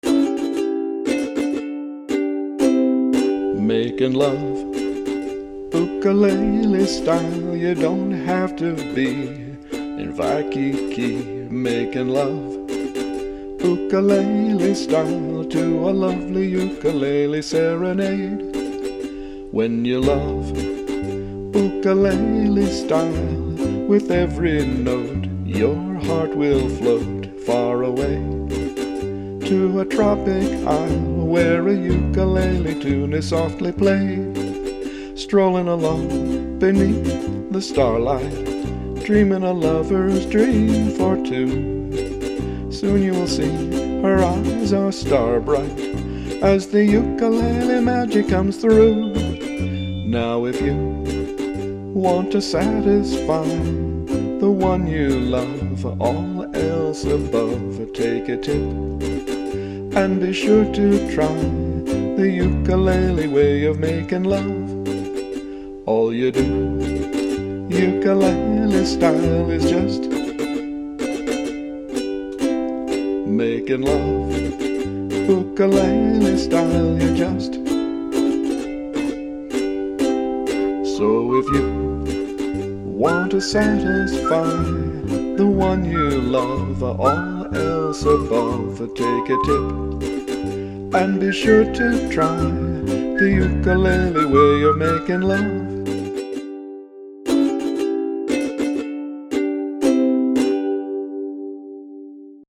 Futulele
I sang, played the uke part on the iPad, and whistled.